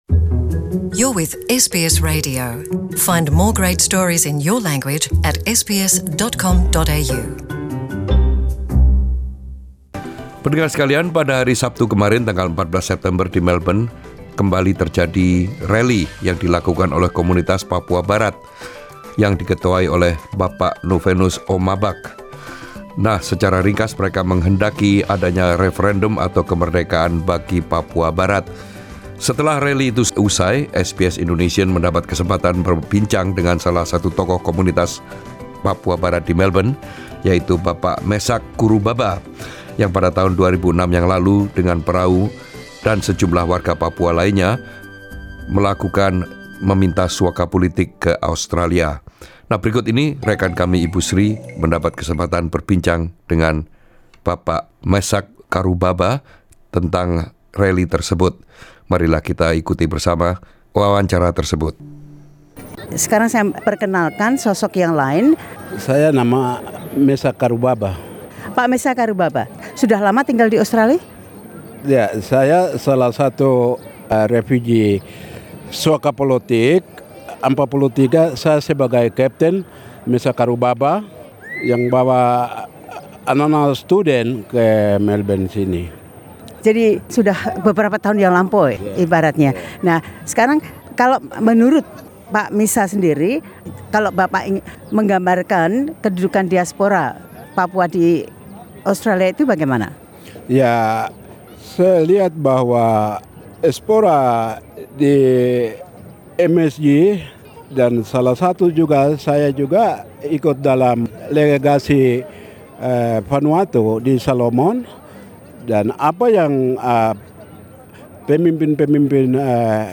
talks to SBS Indonesian after the rally in Melbourne, 14 Sep 2019.